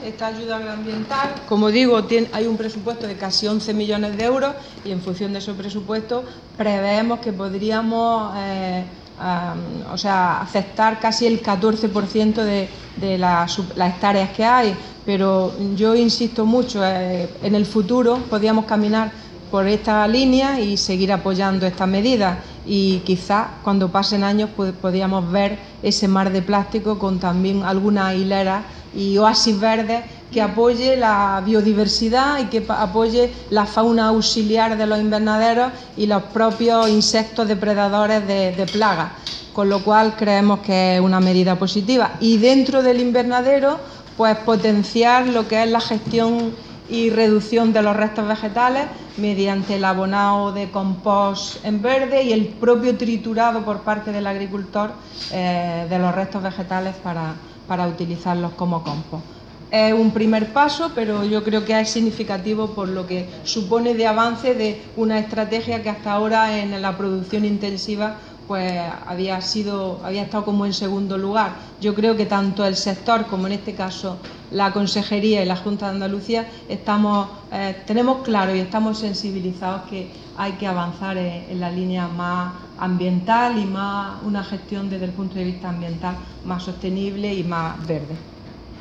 Carmen Ortiz durante la rueda de prensa celebrada en Almería
Declaraciones de Carmen Ortiz sobre ayuda agroambiental para cultivos de invernadero